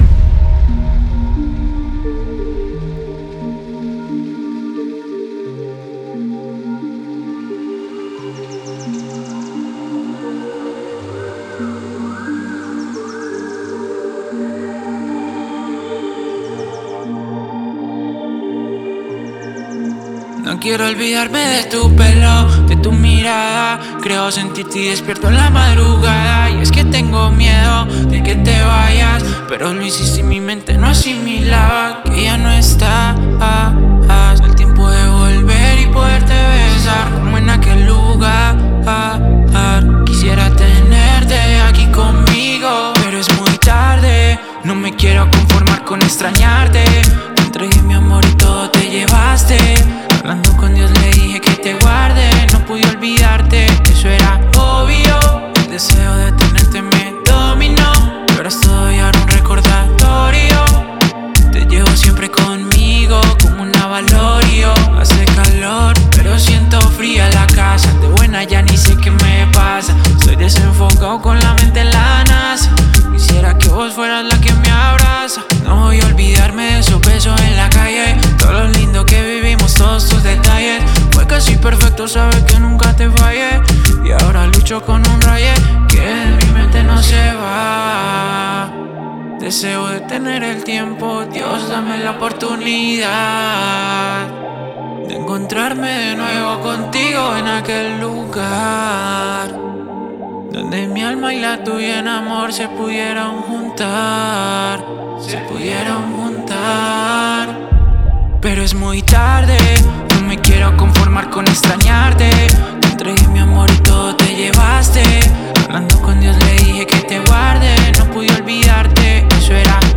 Música urbana